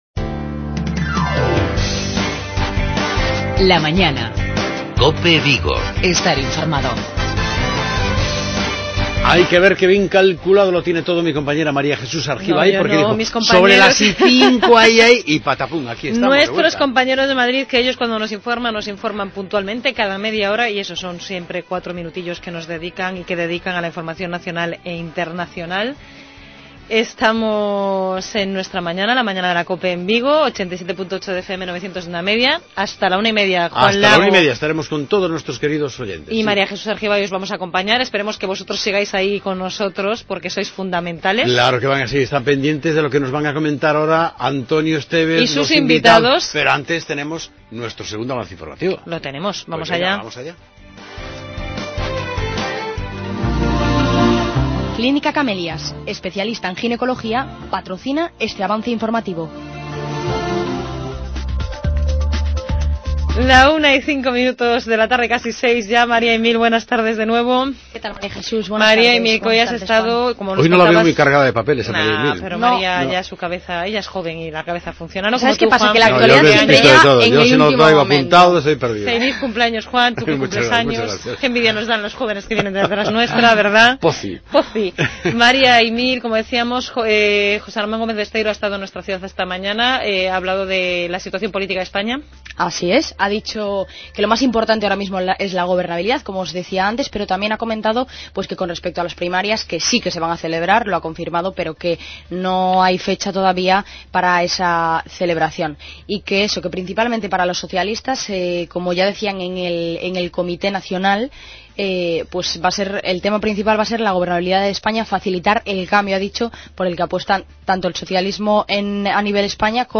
Redacción digital Madrid - Publicado el 07 mar 2016, 13:39 - Actualizado 17 mar 2023, 08:46 1 min lectura Descargar Facebook Twitter Whatsapp Telegram Enviar por email Copiar enlace Tertulia deportiva en la que analizamos la durísima derrota por 7-1 encajada por el Celta frente al Real Madrid, el pasado sábado en el Santiago Bernabéu.